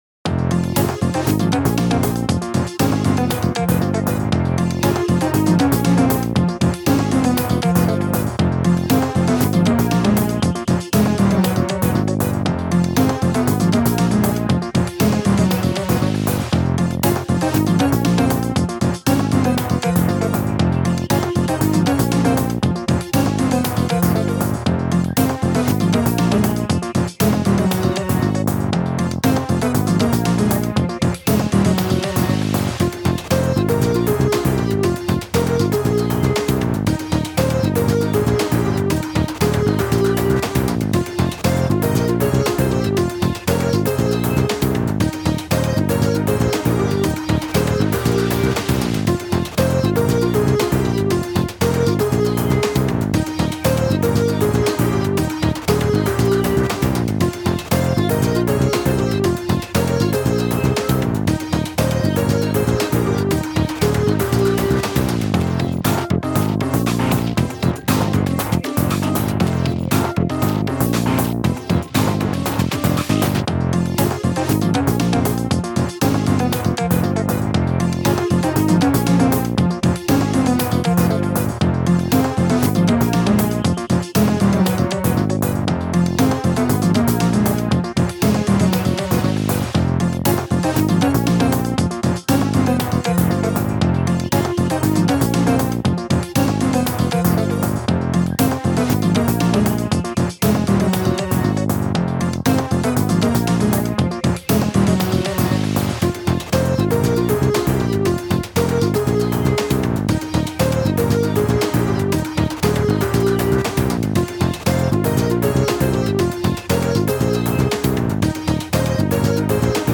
Rhythmic track for retro and boss battle.